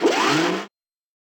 mediumMove.ogg